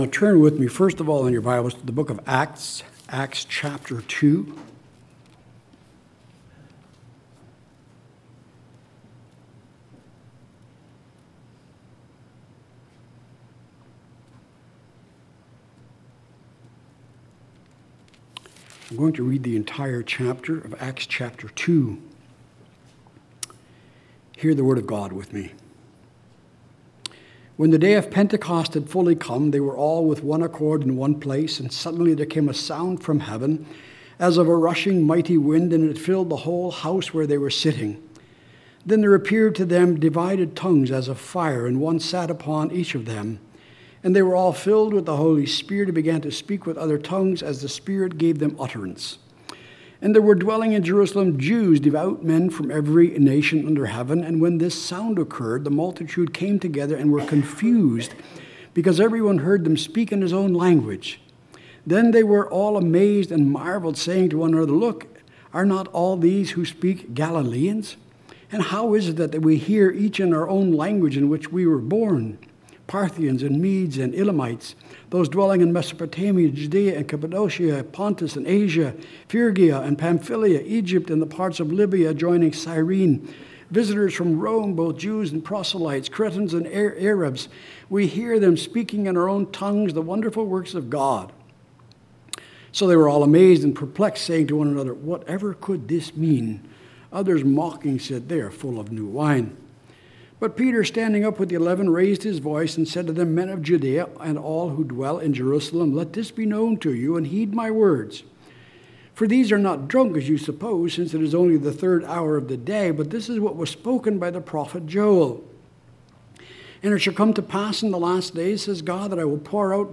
2021 Pentecost in the Process of Salvation Preacher